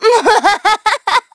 Xerah-Vox_Happy1_Madness.wav